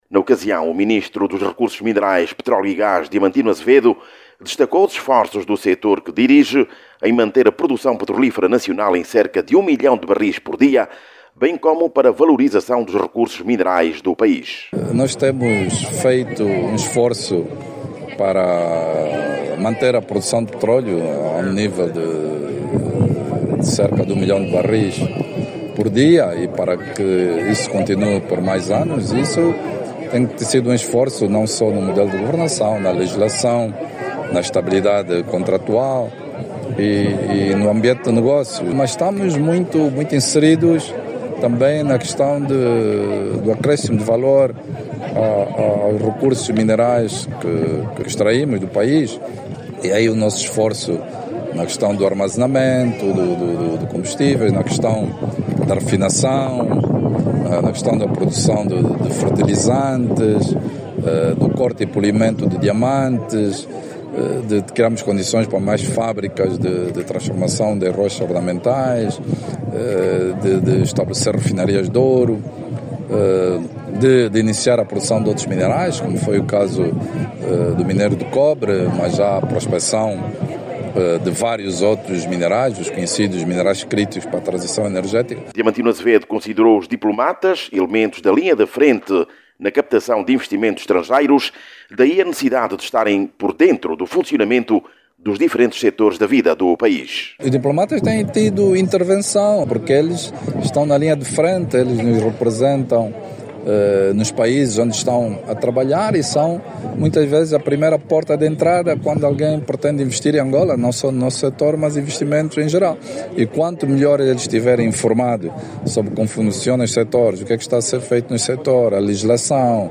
O dado foi avançado nesta terça-feira, em Luanda, pelo ministro dos Recursos Minerais, Petróleo e Gás, Diamantino de Azevedo, durante a aula magna destinada aos formandos do Programa de Treinamento de Diplomatas do Futuro da Academia Diplomática Venâncio de Moura.